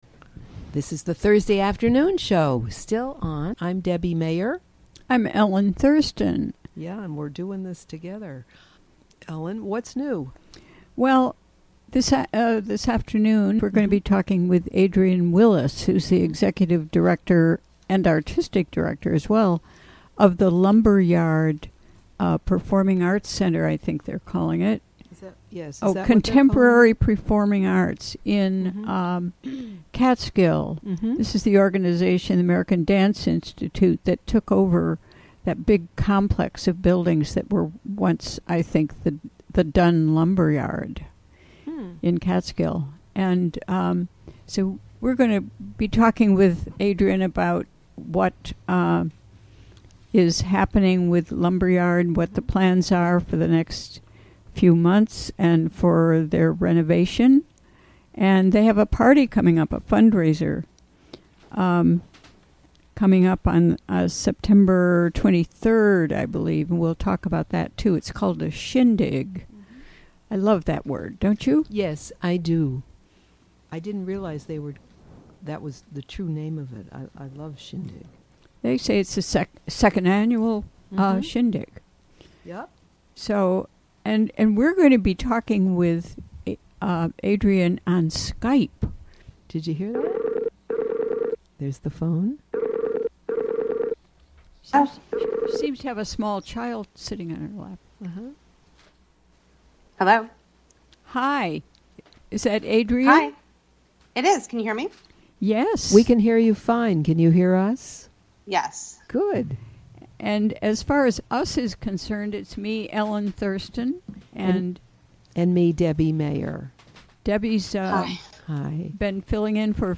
Recorded during the WGXC Afternoon Show on September 7, 2017.